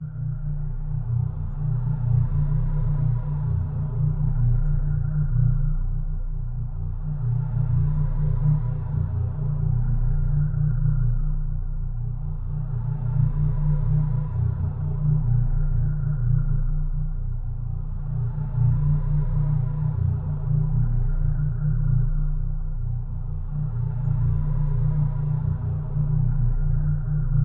发动机噪音低
描述：低引擎嗡嗡声
标签： 呜呜 空间 发动机哼
声道立体声